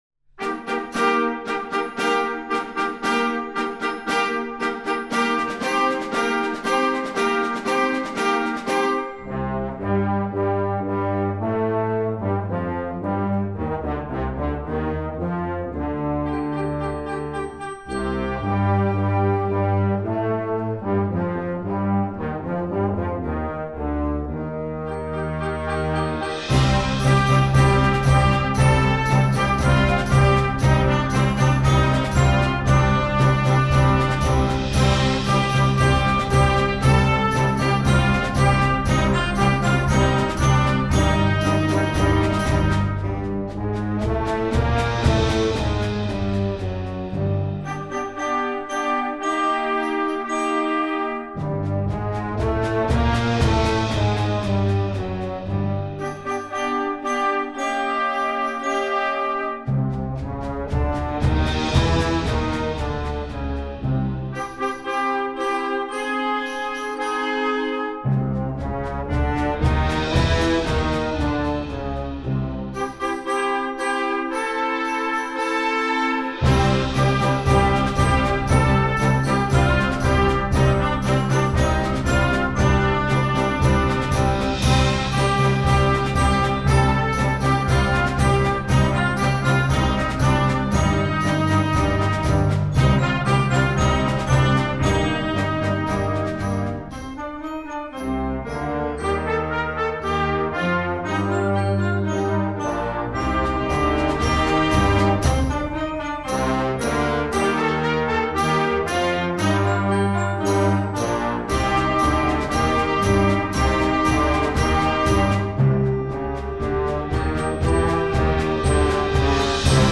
classical, children, french